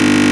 bd_roll.ogg